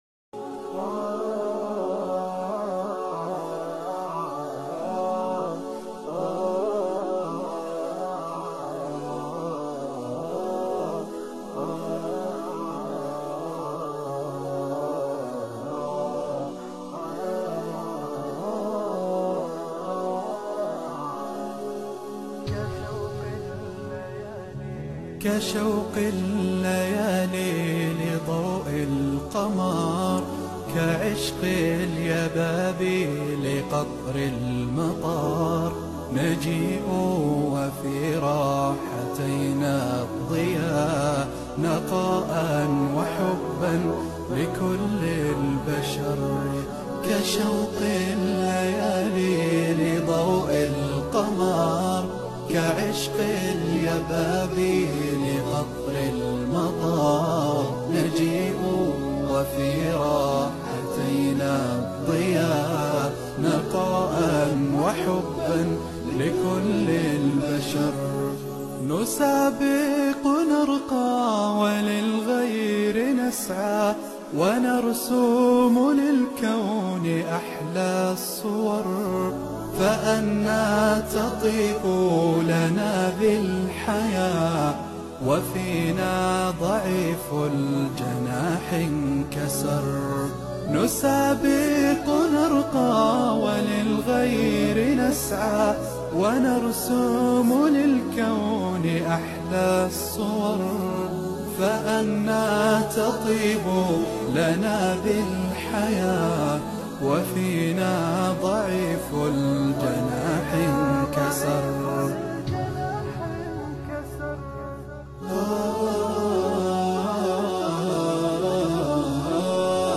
BEAUTIFUL NASHEED🤍😊 USE HEADPHONE FOR